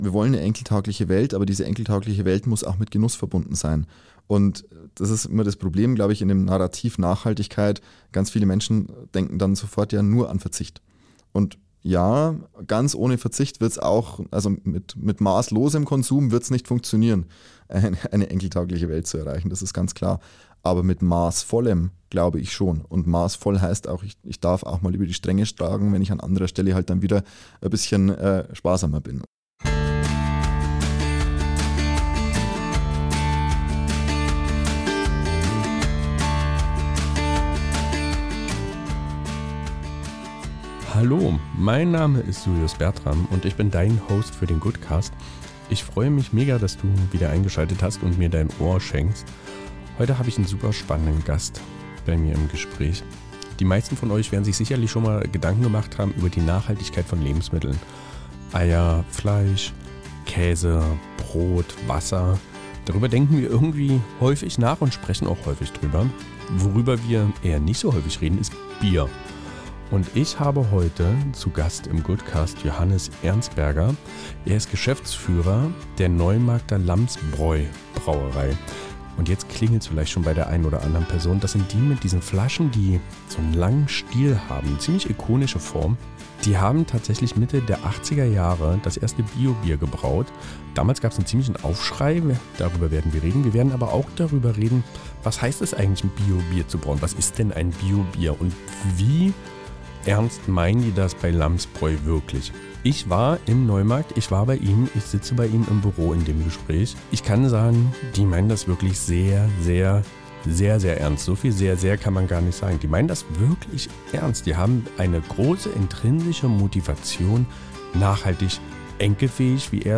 Was macht eine Brauerei besonders – und wie bleibt man sich dabei treu? Natürlich geht es dabei um ganz viel Bier! Ein warmes, ehrliches Gespräch über Verantwortung, Familiengeschichte und Zukunftsideen.